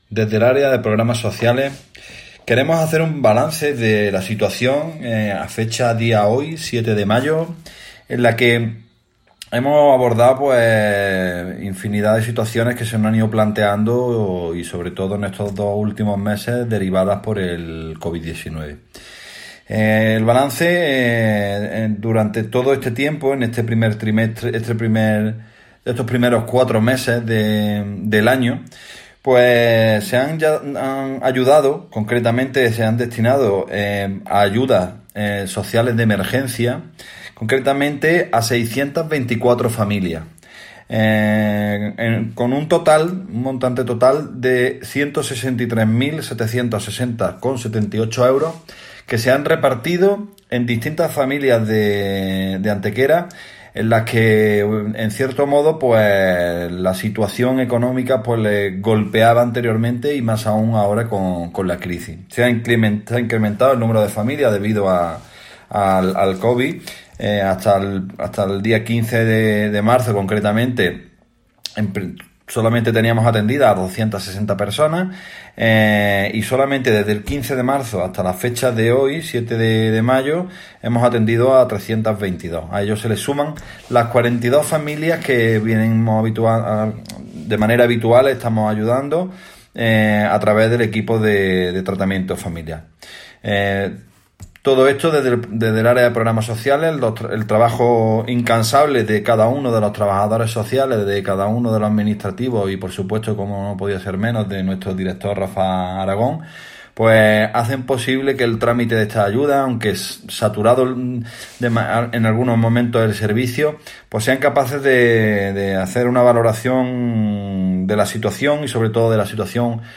El teniente de alcalde delegado de Programas Sociales, Alberto Arana, informa sobre el balance de ayudas de emergencia social que han sido concedidas y abonadas por el Ayuntamiento de Antequera durante los cuatro primeros meses de año.
Cortes de voz